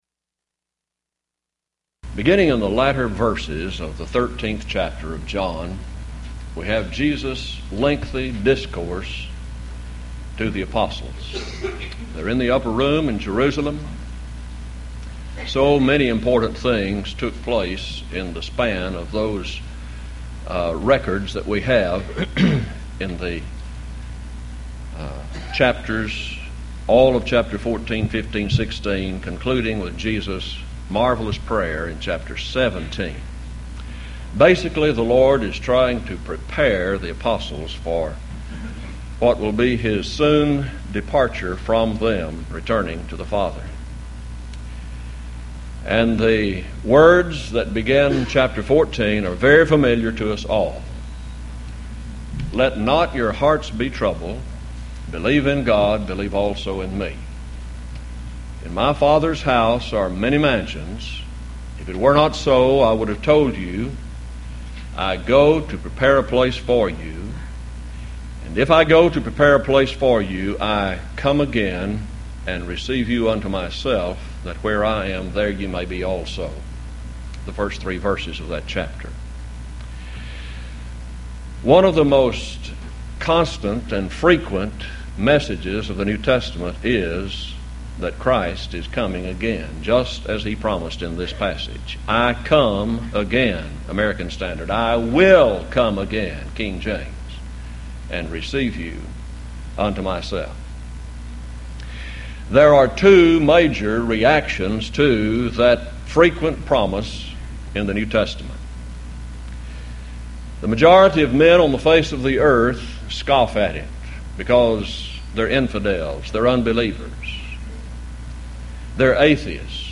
Series: Houston College of the Bible Lectures Event: 1997 HCB Lectures